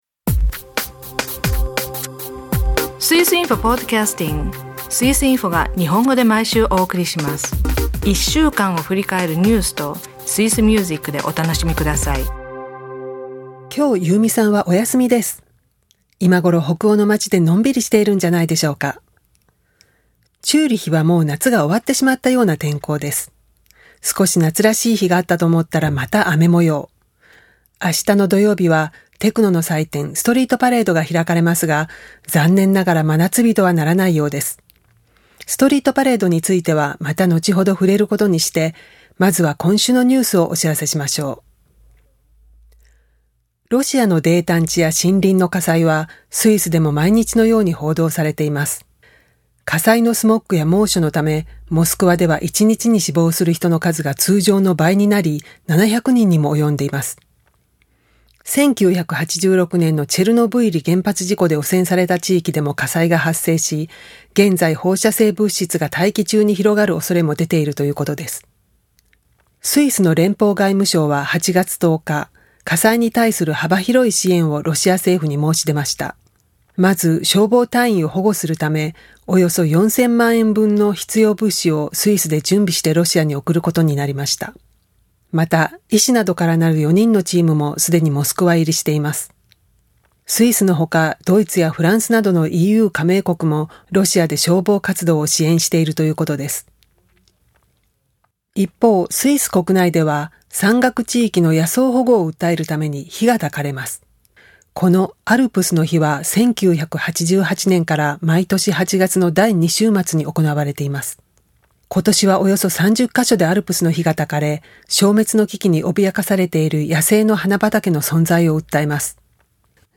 ロシアの泥炭地大火災の消防活動にスイスからも援助。朗読のウーリはお金が底をつき、落ち込んでしまいます。
今週はこのテーマ曲をどうぞ。